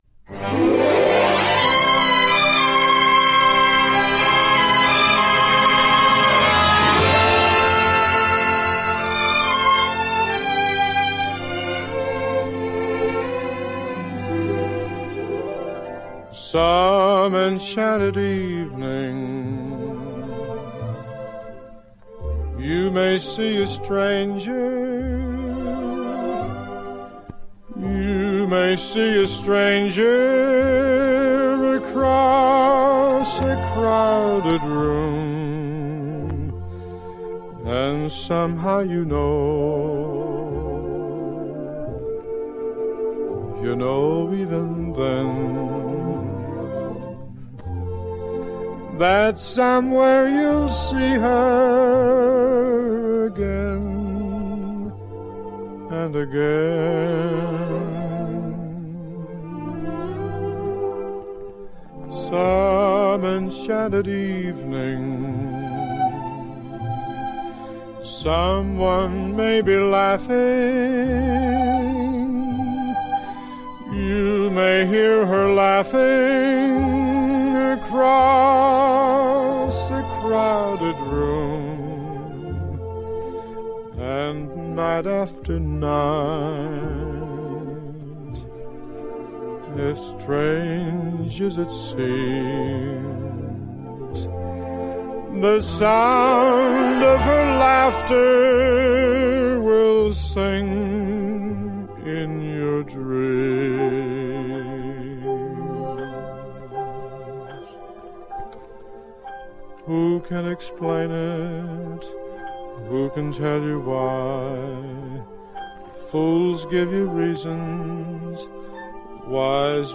С пластинки